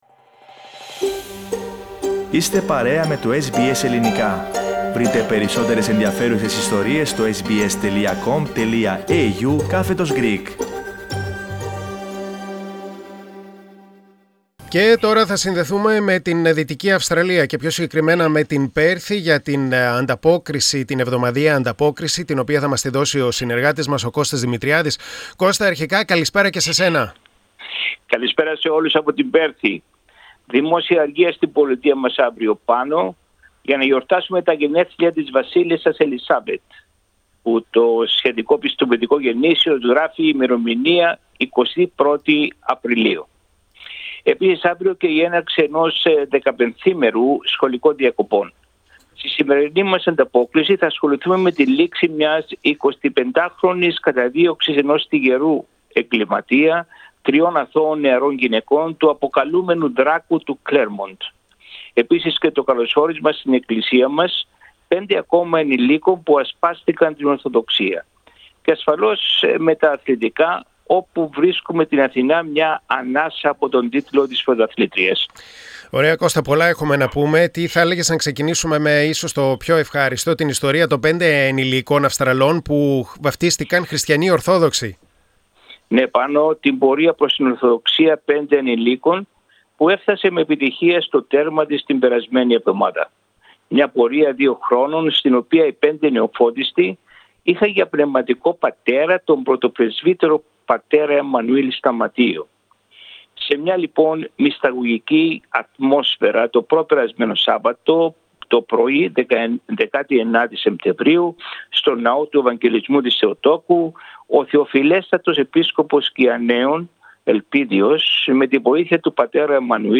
Η εβδομαδιαία ανταπόκριση από την Δυτική Αυστραλία